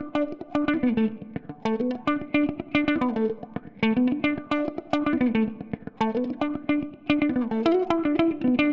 35 Guitar PT2.wav